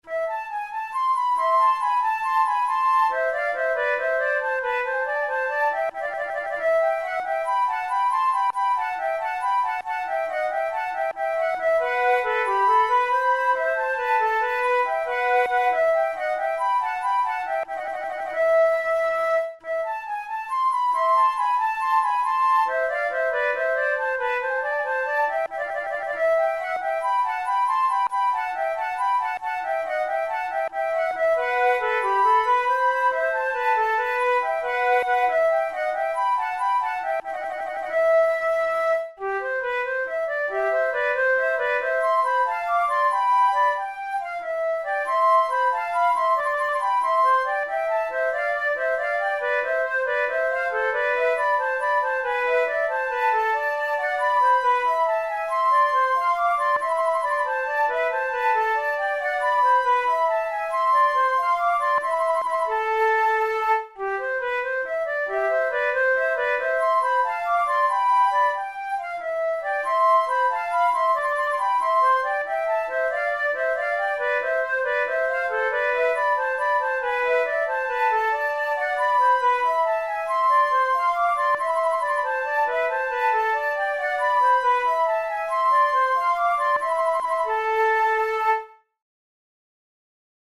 This is the fourth and final movement of a sonata in A minor for two flutes by the German Baroque composer and music theorist Johann Mattheson.
Categories: Baroque Jigs Sonatas Written for Flute Difficulty: intermediate